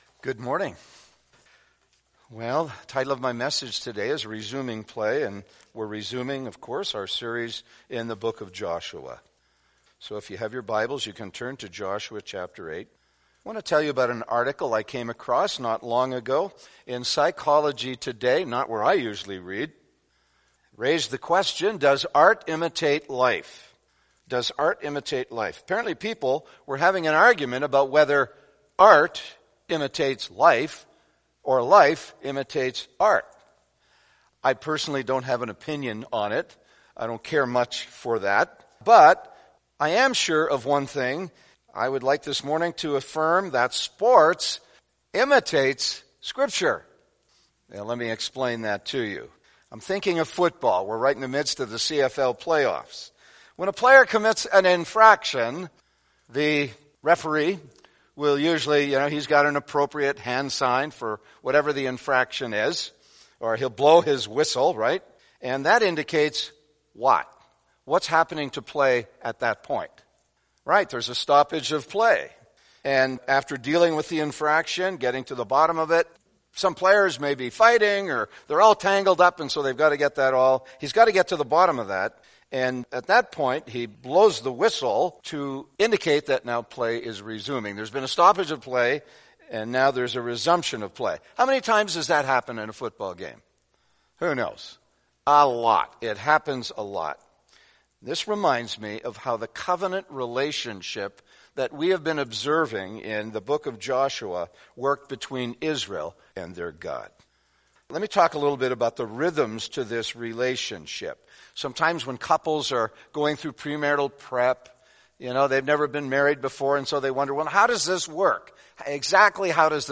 Sermons | Balmoral Bible Chapel